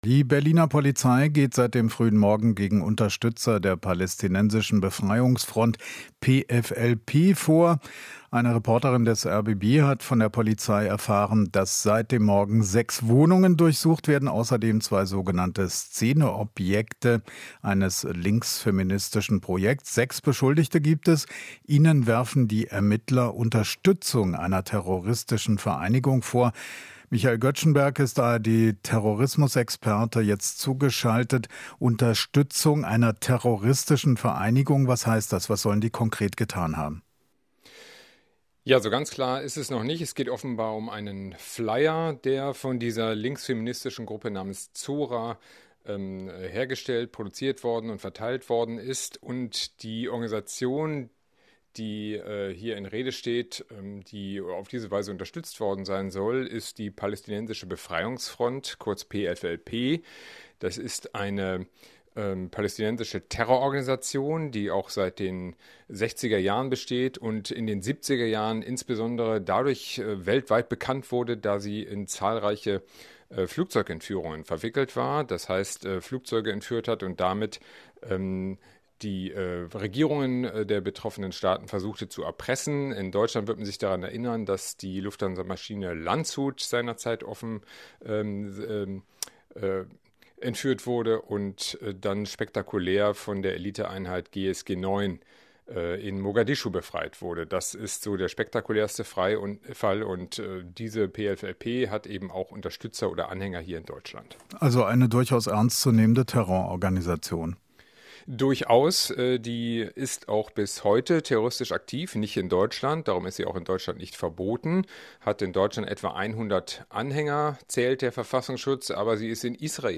Interview - Anti-Terror-Durchsuchungen in Berlin